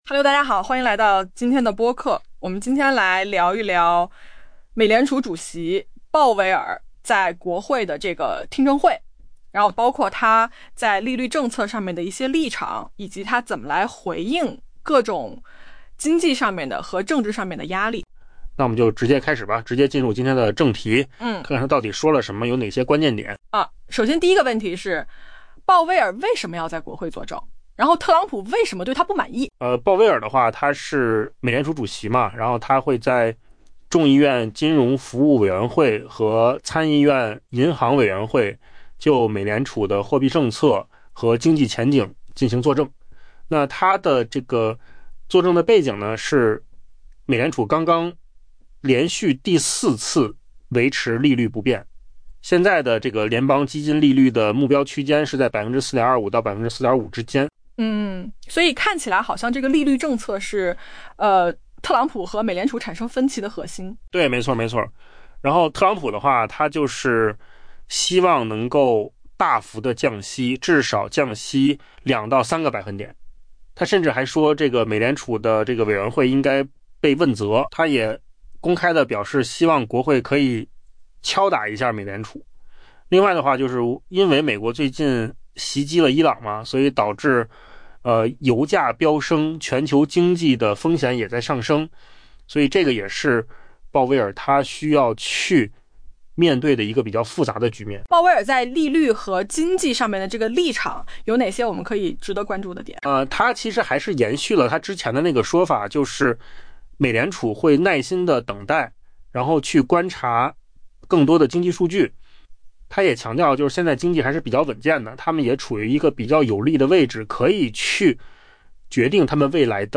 AI播客：换个方式听新闻（音频由扣子空间生成） 下载mp3